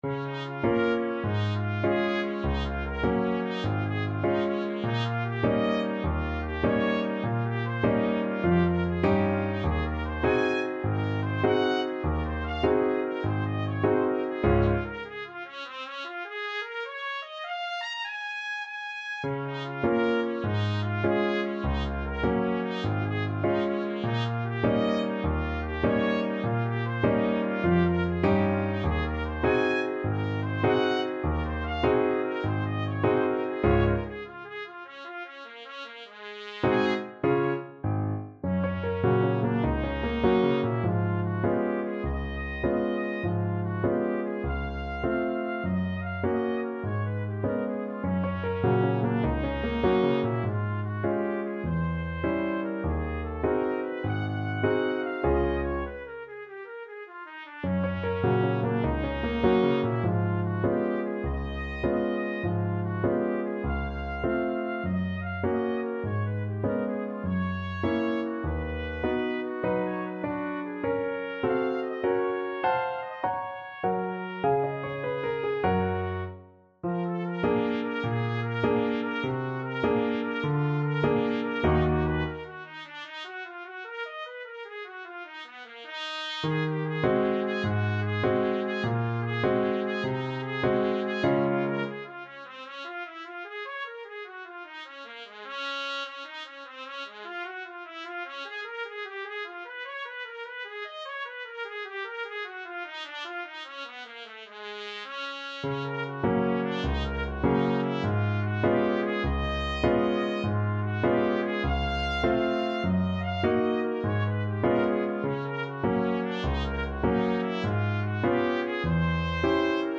Trumpet version
4/4 (View more 4/4 Music)
Lightly = c. 100
F4-Bb6
Trumpet  (View more Advanced Trumpet Music)
Jazz (View more Jazz Trumpet Music)
Rock and pop (View more Rock and pop Trumpet Music)